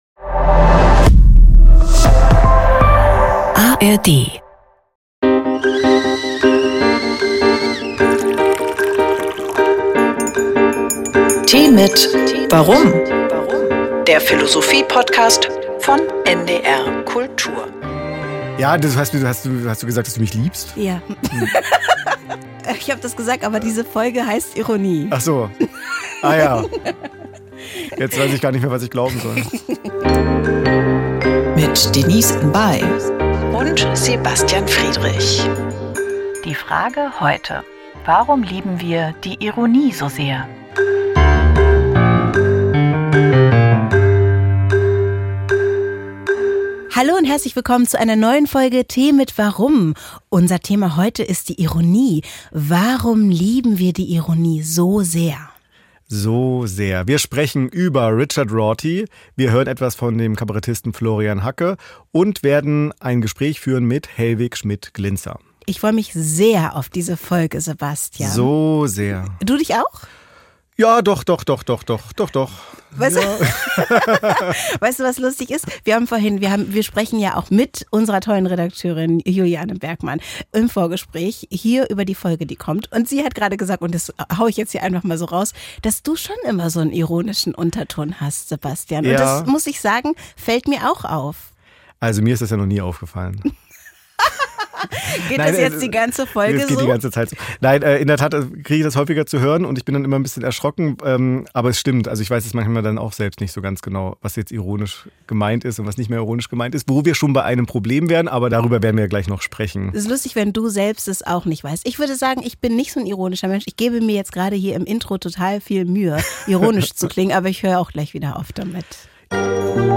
Im Gespräch mit dem Sinologen Helwig Schmidt-Glintzer hinterfragen sie, ob der Abschied von der Wahrheit - wie ihn Rorty und andere Poststrukturalisten propagierten - verfrüht war und inwiefern Ironie helfen kann, kulturelle Distanzen zu einem Land wie China zu überwinden.